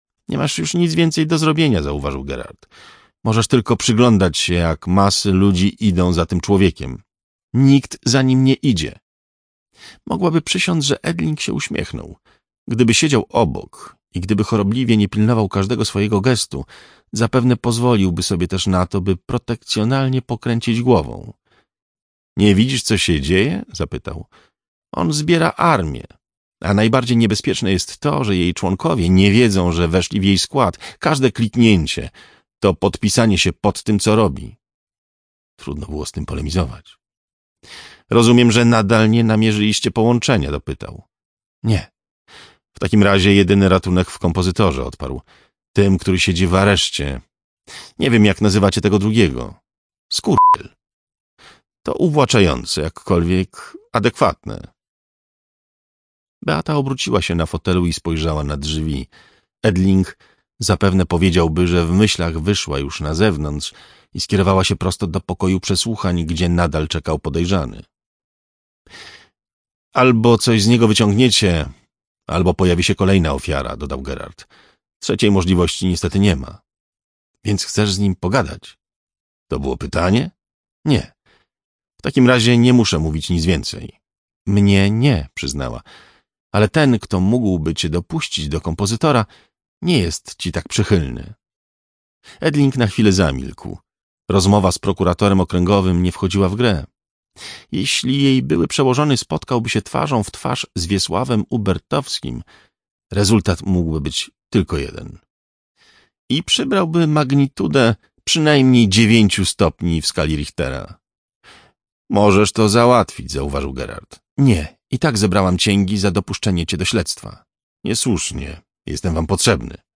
Trzymającej w napięciu powieści kryminalnej „Behawiorysta” Remigiusza Mroza w interpretacji Jacka Rozenka słuchaj w Radiu Kielce od poniedziałku do piątku o godz. 10.45 i 21.45.